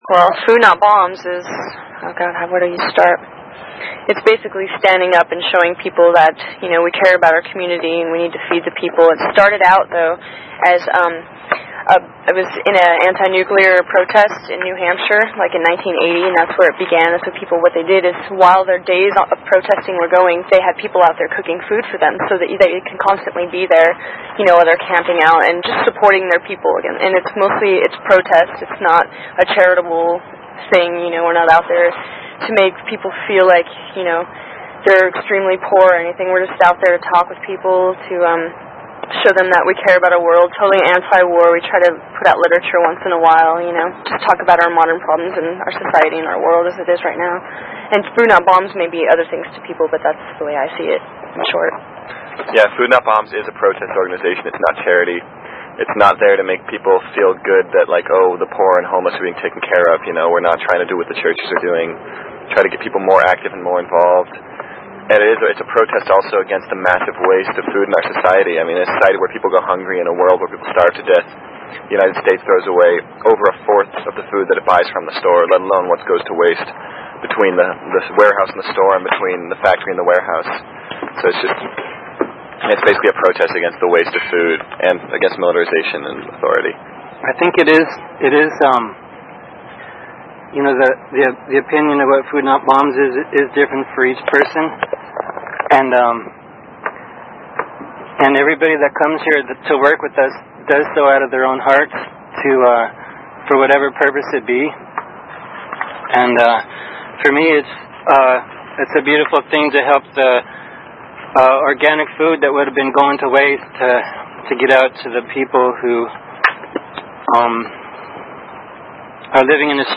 Interview with a few Food Not Bombs SC activists on a fine September afternoon
FNBInterview.mp3